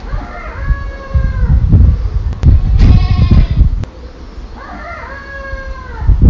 In de ochtend staat ze als een volwassen haan te kraaien, eigenlijk alleen ’s ochtends, zo na een uur of negen is ze weer gewoon kip en left ze enthousiast haar dagelijkse ei.
Multimediakip die denkt dat ze een haan is… 😀